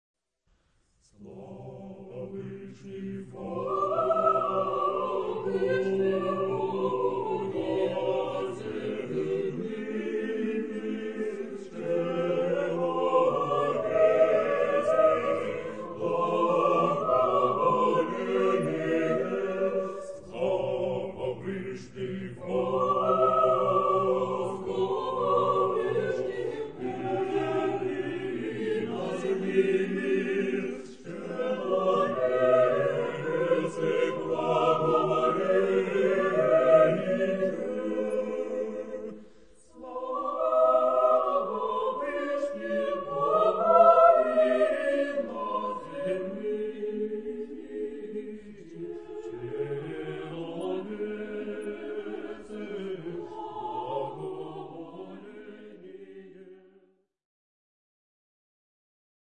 Genre-Stil-Form: geistlich ; liturgische Hymne (orthodox) ; orthodox ; Psalm Charakter des Stückes: fröhlich ; majestätisch ; fromm
SATB (6 gemischter Chor Stimmen )
Tonart(en): C-Dur